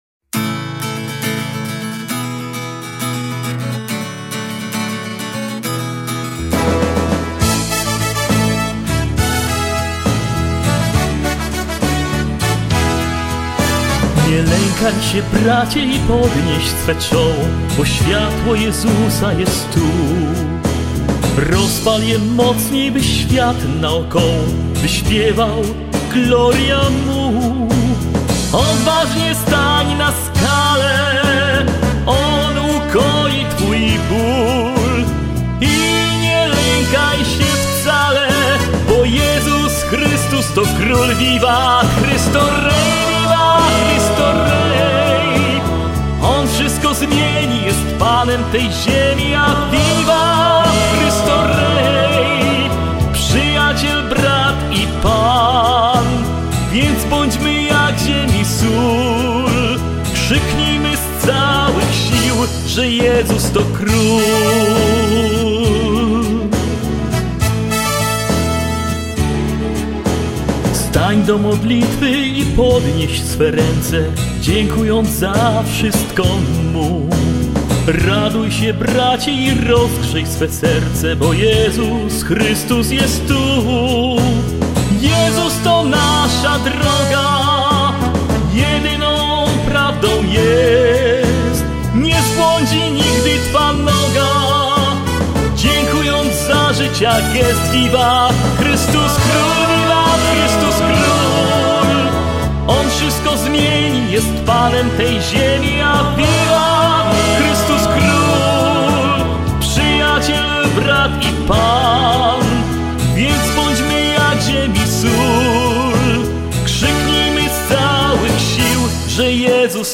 Hymn na część Chrystusa Króla